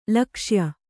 ♪ lakṣya